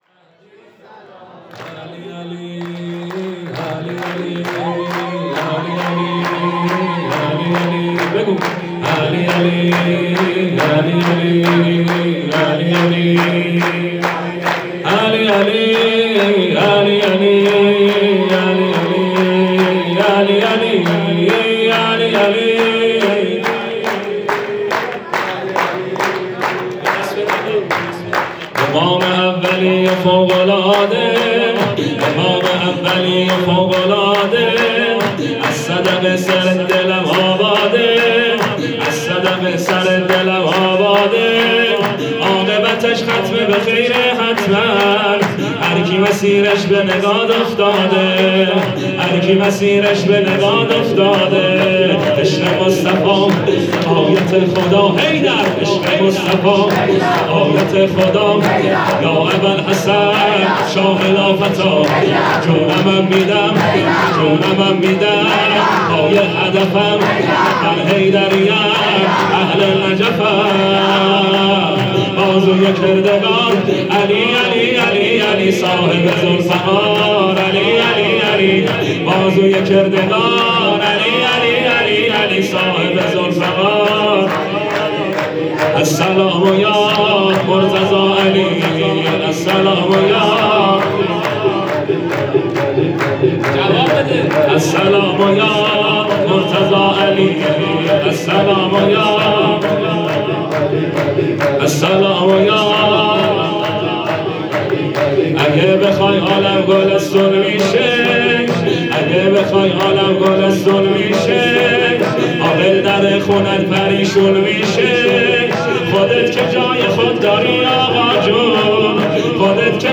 مولودی_شور